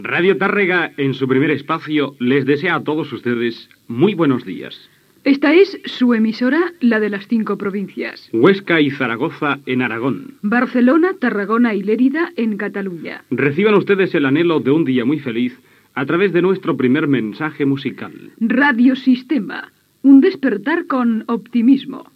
Bon dia i identificació com "la emisora de las cinco províncias".